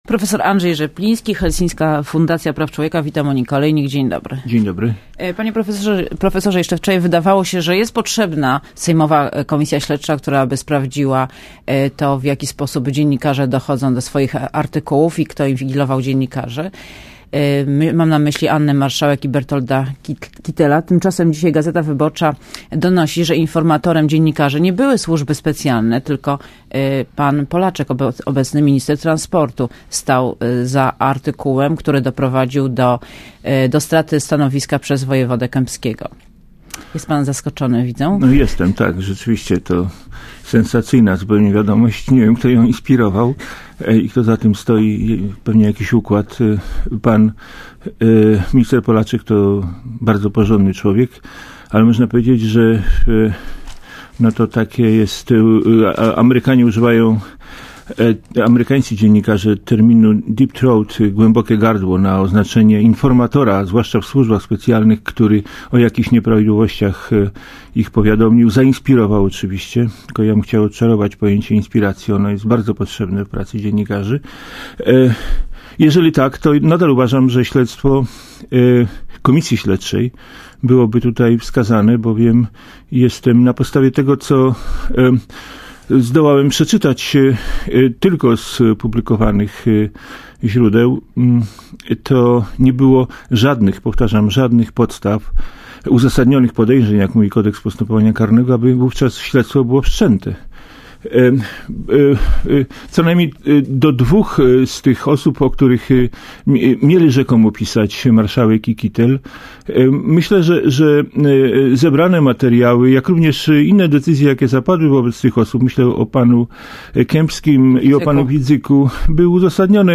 Posłuchaj wywiadu Prof. Andrzej Rzepliński, Helsińska Fundacja Praw Człowieka.